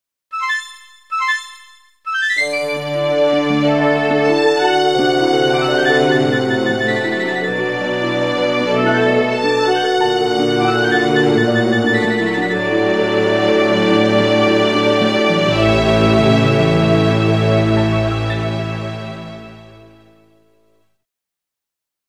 Tags: dungeons and dragons D&D nerd stuff ambient funny